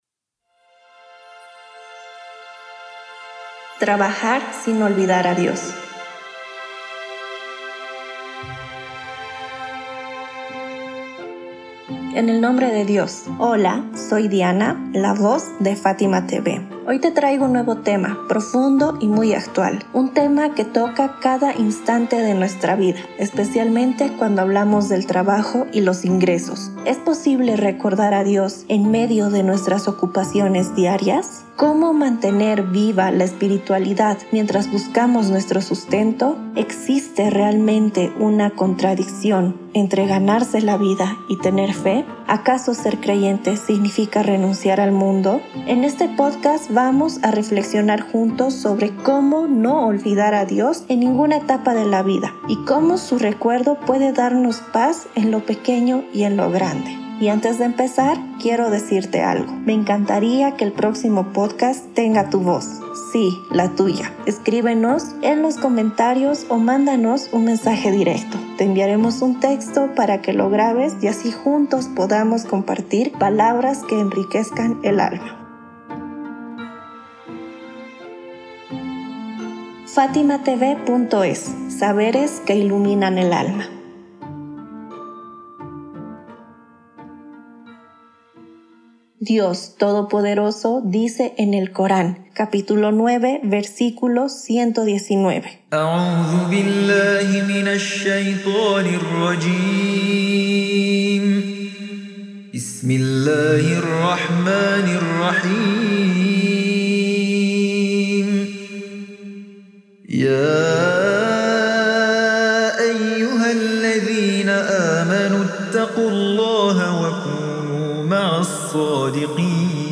🎙 Locutora: